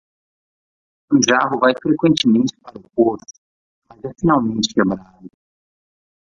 Pronúnciase como (IPA)
/keˈbɾa.du/